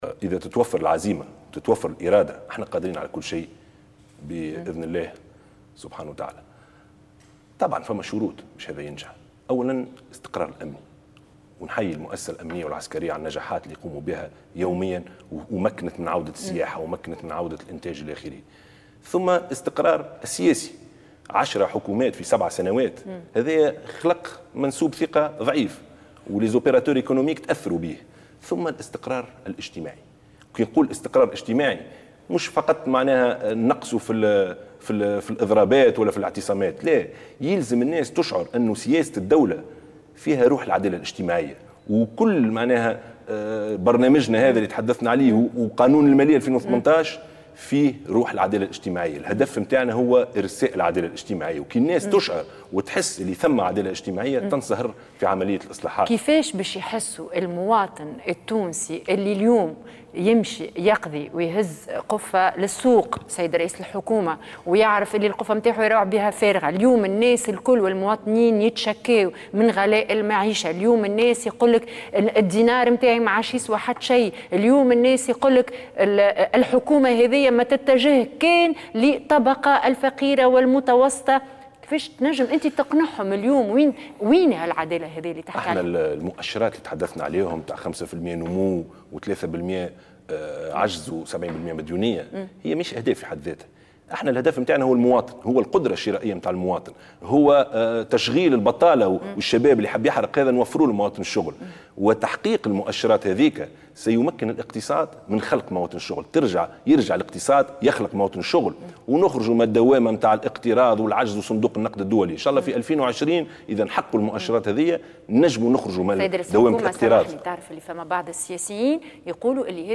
قال رئيس الحكومة يوسف الشاهد، في حوار مع إحدى القنوات الخاصة، إن تونس قادرة على الخروج من أزمتها وتجاوز دوامة الاقتراض شرط توفر عدة شروط من بينها توفر الأمن الذي يمكن من عودة الانتاج وانتعاش السياحة، إضافة إلى الاستقرار السياسي والاجتماعي لتعزيز ثقة المستثمرين.